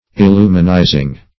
Illuminizing synonyms, pronunciation, spelling and more from Free Dictionary.
illuminizing.mp3